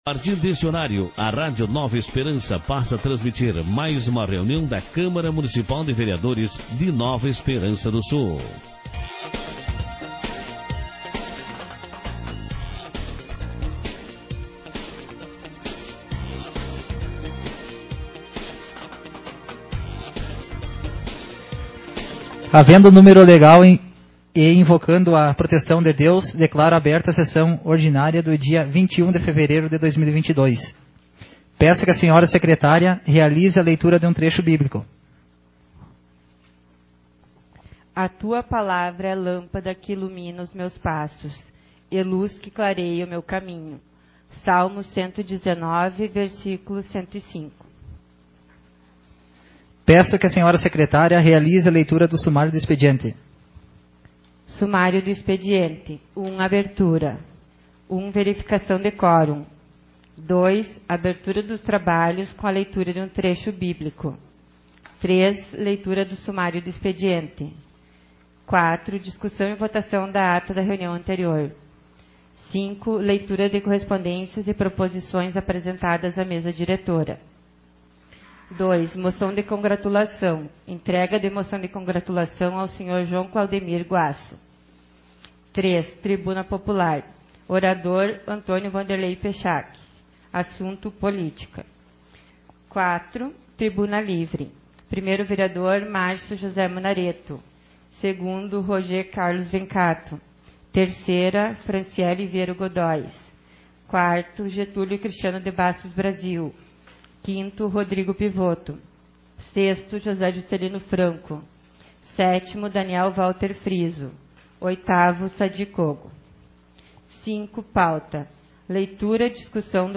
Sessão Ordinária 03/2022